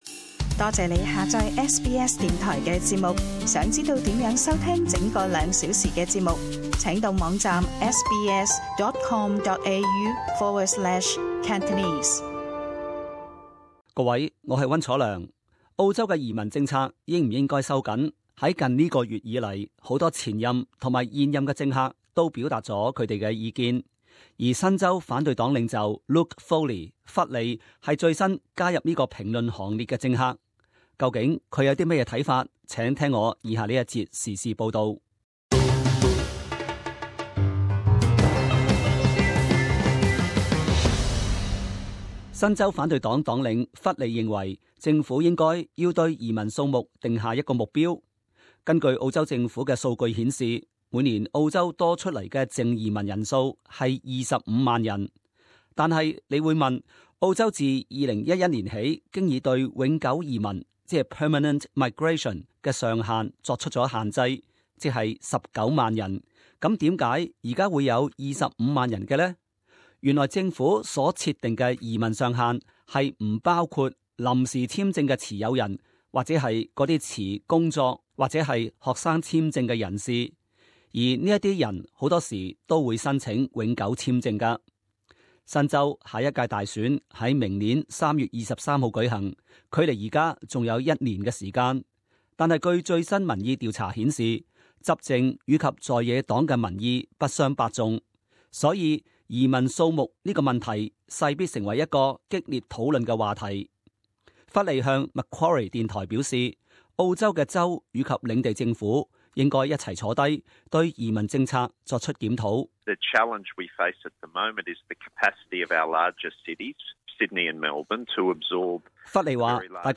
【時事報導】 另一政客認爲要為移民數目設上限